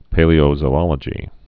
(pālē-ō-zō-ŏlə-jē)